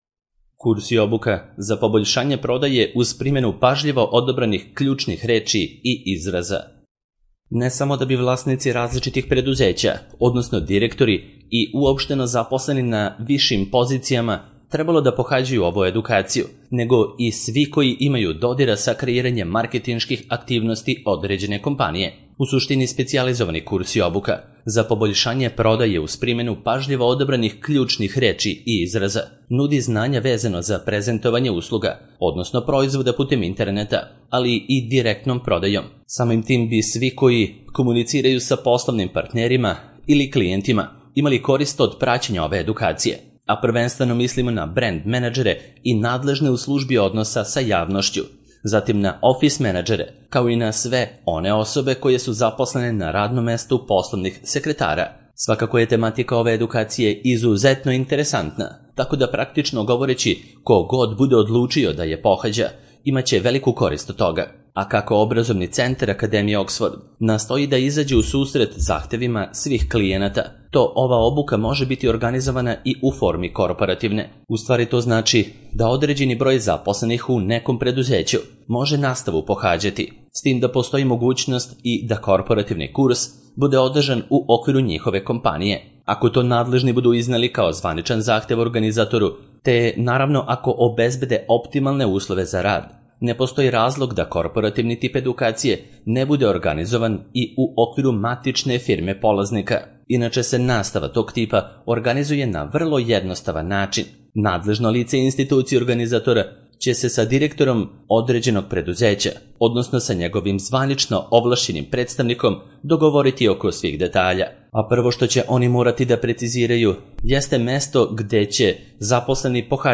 Audio verzija teksta